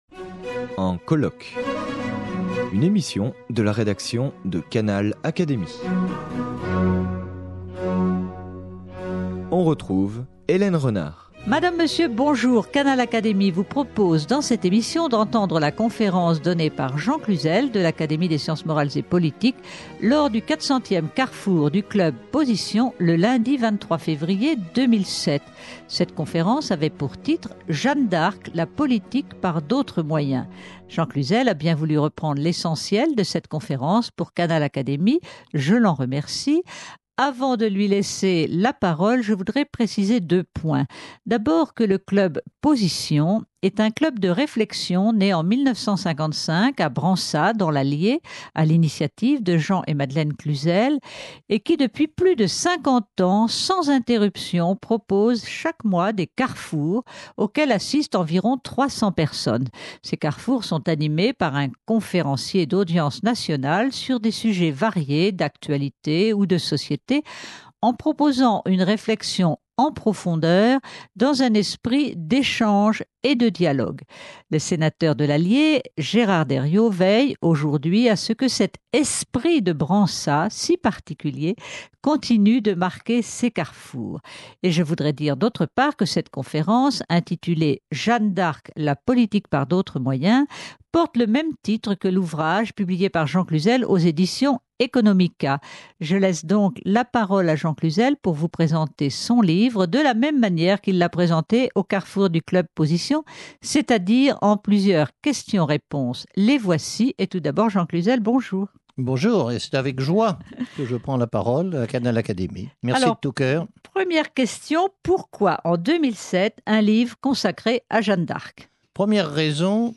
L’intuition politique de Jeanne d’Arc, tel est le thème de la conférence donnée par Jean Cluzel lors du 400 ème Carrefour de Positions à Bransat dans l’Allier. Résumant l’état de la France en 1429 et le parcours de Jeanne, Jean Cluzel insiste sur la stabilité politique qui a suivi le martyre de Jeanne, grâce à deux autres femmes qui ont su garder son héritage politique et spirituel : les filles de Louis XI, Anne de France (Anne de Beaujeu) et Jeanne de France.